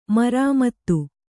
♪ marāmattu